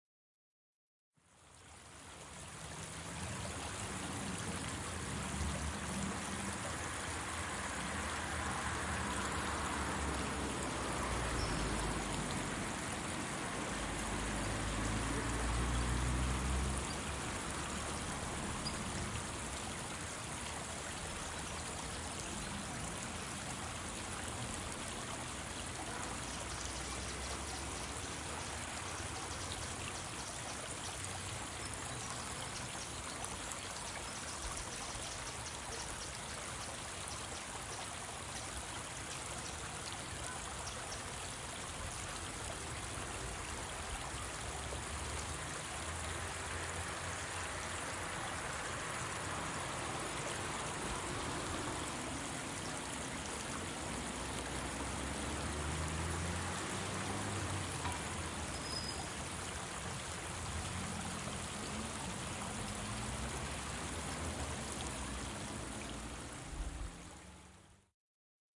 描述：放大荷兰Zeist的H4n X / Y立体声现场录音。公园，住宅的一般氛围。
Tag: 场记录 背景音 音景 环境 杜TCH 背景 ATMOS Zeist的 瀑布 公园 环境 氛围 ATMO 荷兰 一般噪声 荷兰 大气